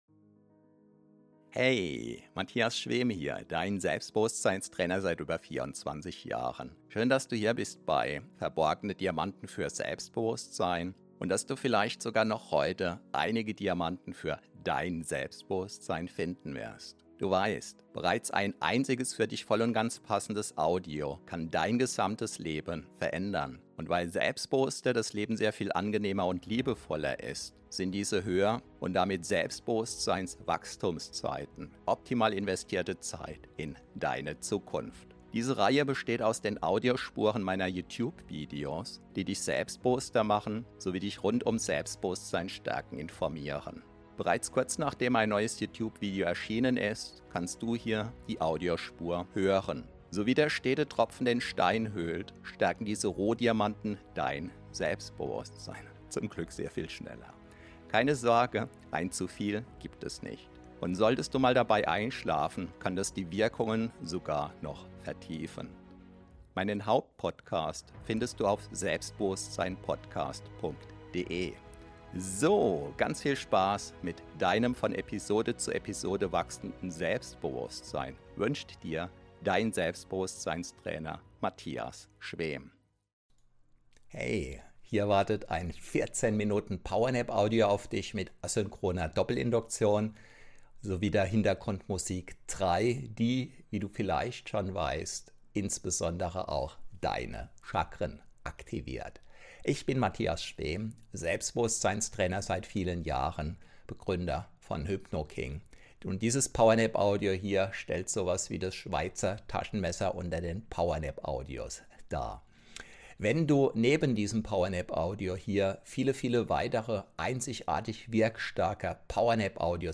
Achtung: Für maximale Wirkung verwende unbedingt hochwertige Kopfhörer!